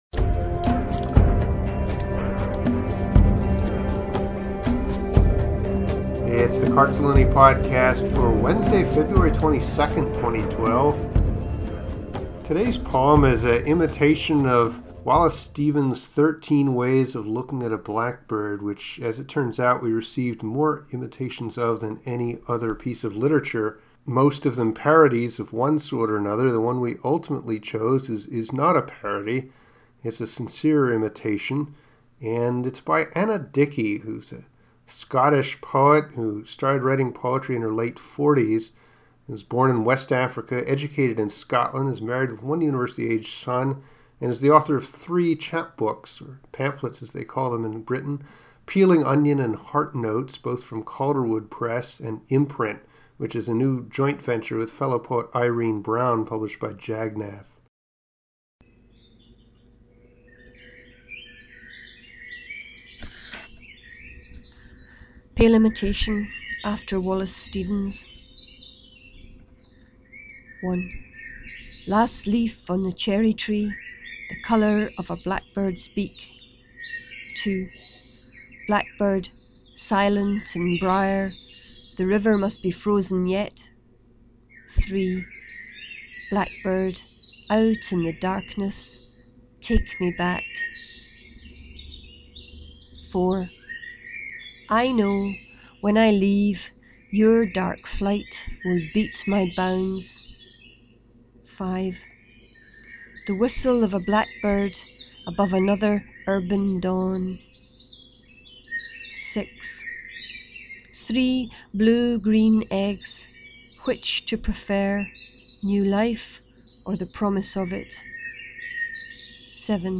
Sound of the blackbird